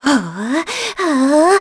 Talisha-Vox_Casting3.wav